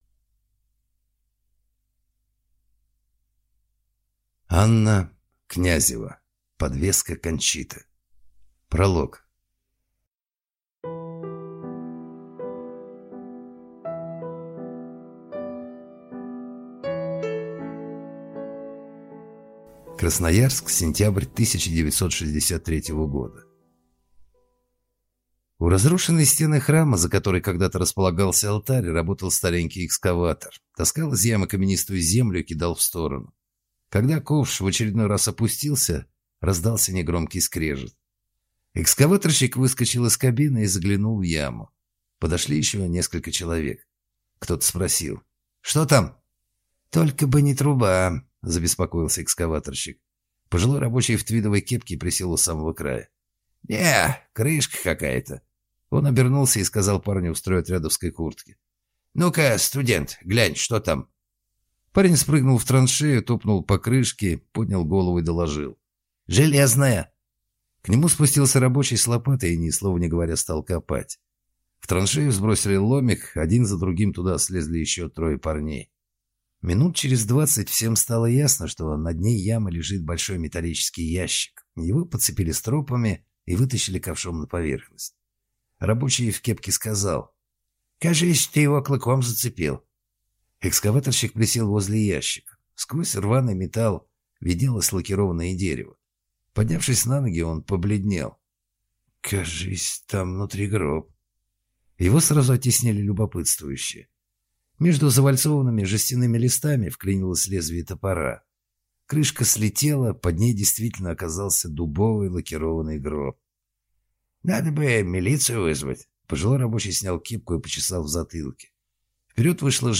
Аудиокнига Подвеска Кончиты | Библиотека аудиокниг